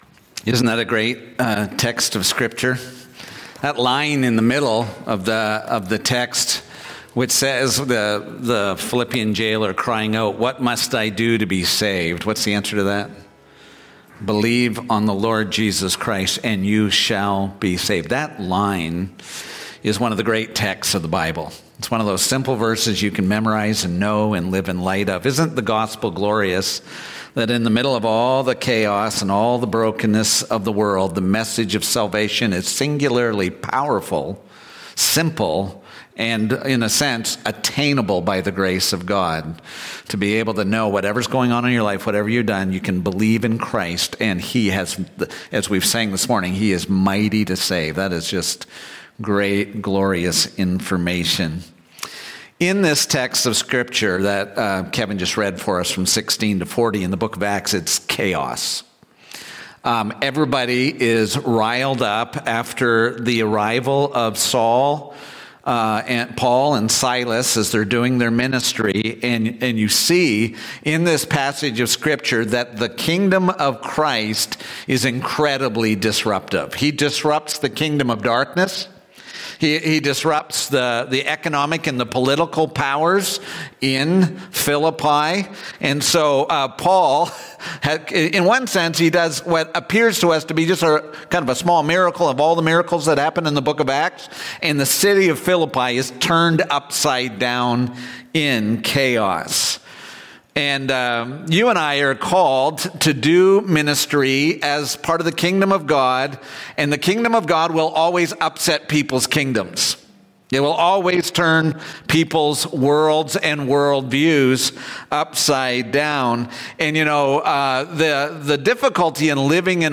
Sermons | Waterbrooke Christian Church